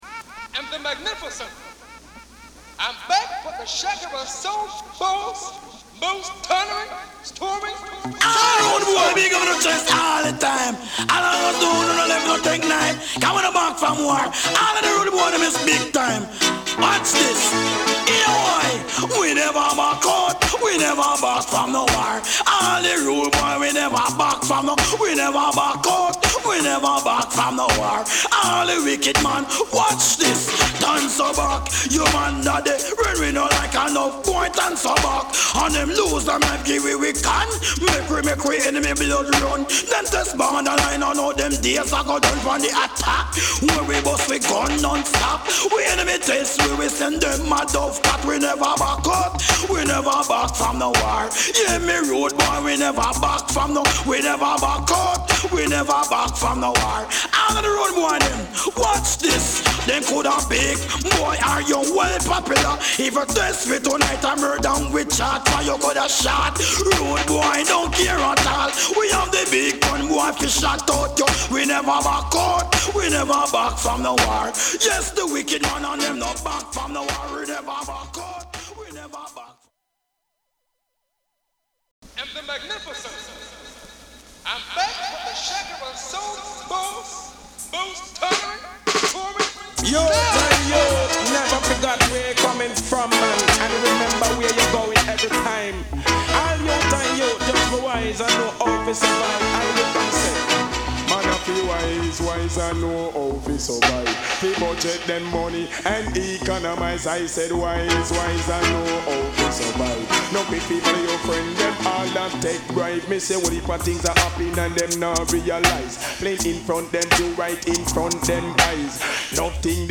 REGGAE / DANCEHALL
プレス・ノイズ有り（JA盤、Reggaeのプロダクション特性とご理解お願い致します）。
歌モノ・オケのイメージが強いが、このジャミー親子版はDJ陣が並んでます！